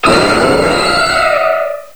cry_not_mega_aerodactyl.aif